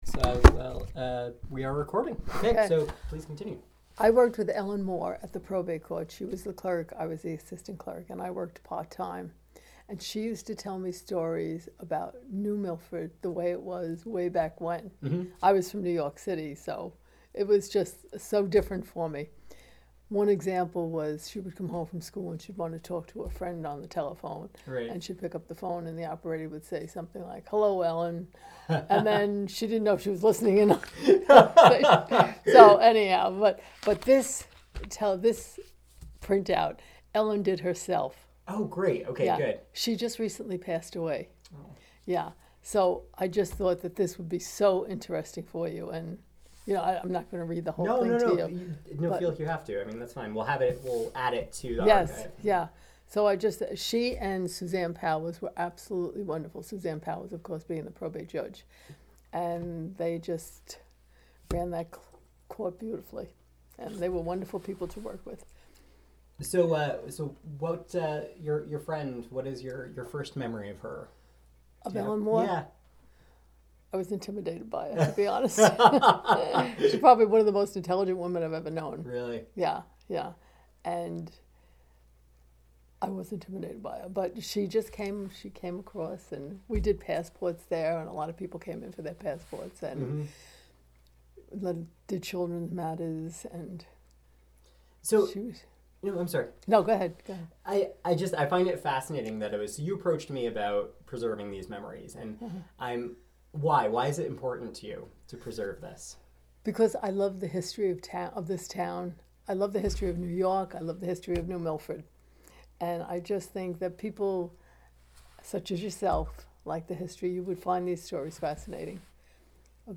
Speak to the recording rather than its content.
Location Burnham Library